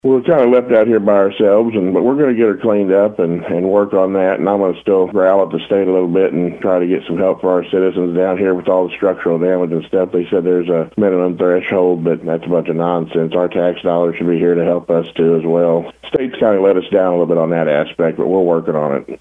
Bismarck Mayor, Seth Radford, says they weren't able to get any help from the state or the county.